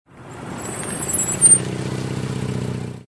혼다 천천히 지나감
혼다 천천히 지나감.mp3